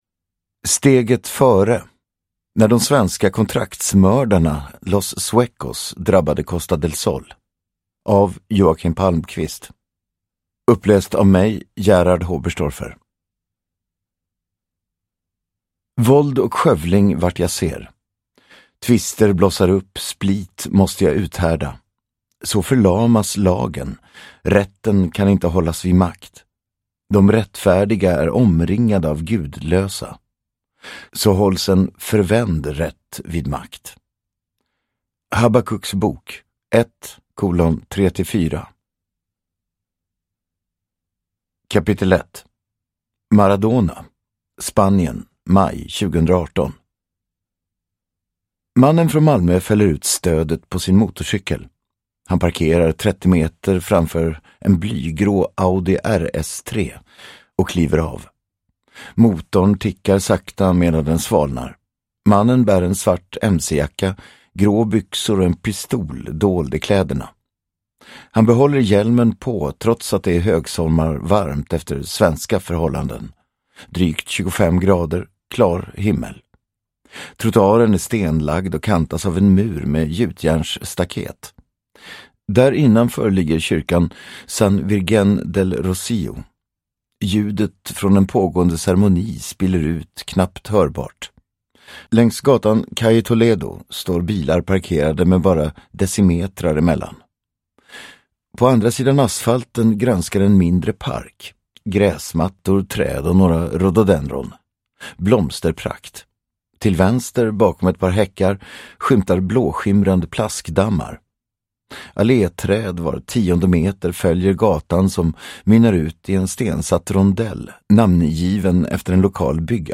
Steget före : när de svenska kontraktsmördarna Los Suecos drabbade Costa del Sol (ljudbok) av Joakim Palmkvist